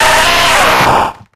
Cries
GEODUDE.ogg